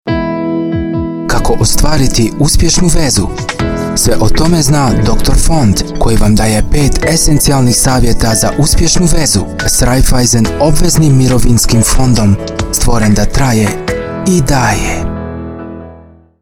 Stimme variabel (von jugendlich frisch über sachlich-seriös bis reisserisch-auffordernd).
gugendlich, frisch, Kroatisch, Bosnisch, Schweizerdeutsch, Sprecher, dynamisch, seriös, reisserisch, professionell, eigenes Aufnahmestudio
Sprechprobe: Werbung (Muttersprache):